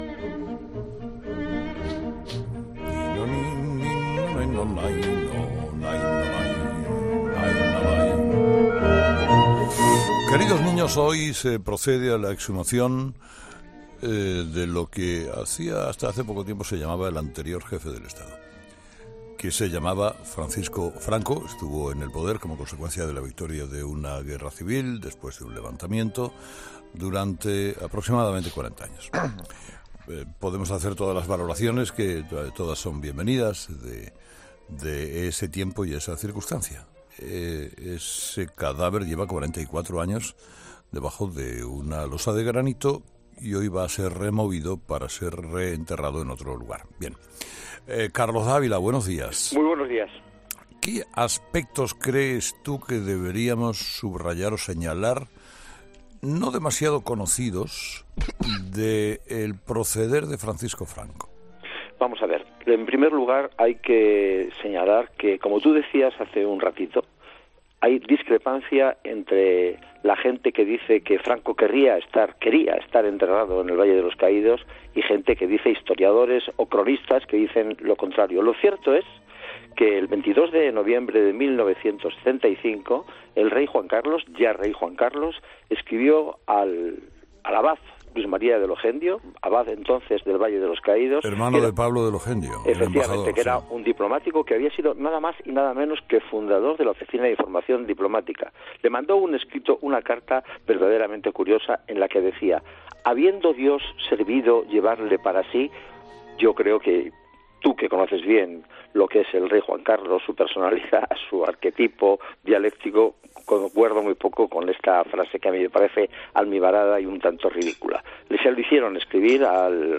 Para conocer los aspectos más desconocidos de su vida, este jueves ha sido entrevistado en 'Herrera en COPE' el veterano periodista Carlos Dávila, que ha dicho que hay "discrepancia" entre  la gente que dice que "Franco quería ser enterrado en el Valle de los Caídos e historiadores y cronistas que dicen lo contrario".